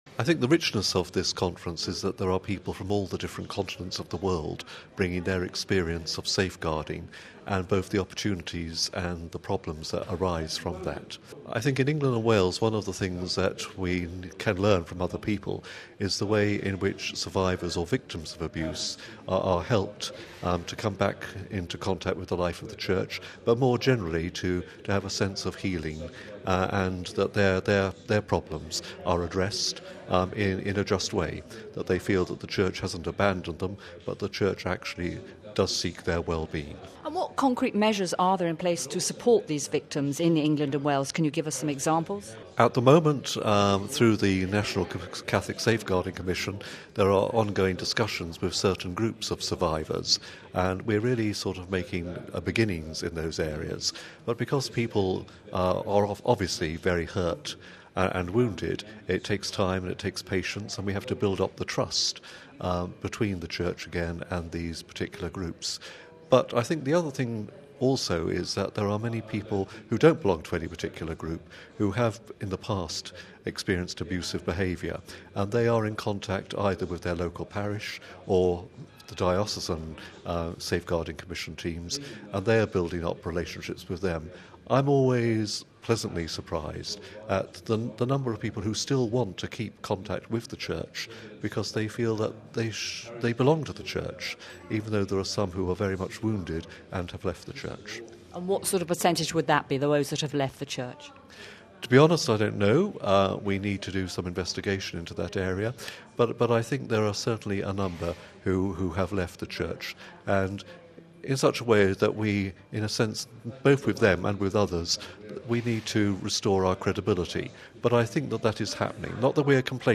Listen to the interview with Bishop Declan Lang: RealAudio